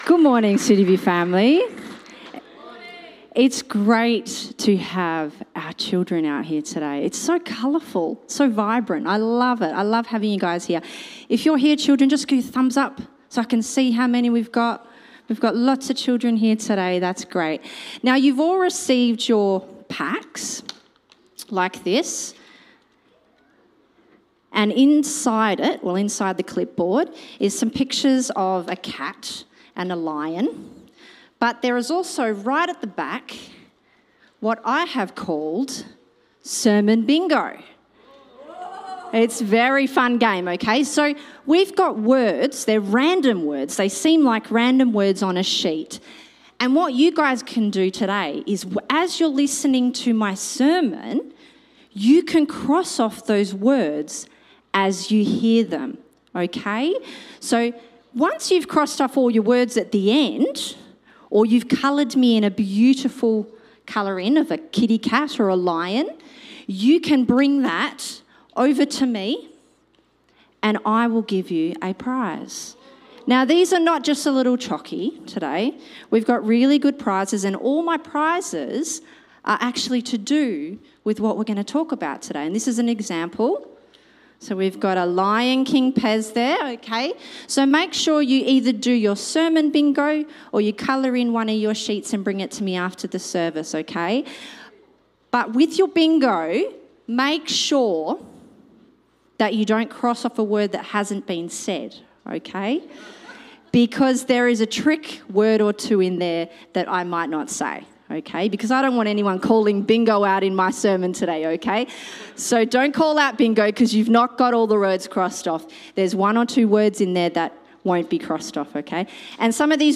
Home Sermons The Lion King in…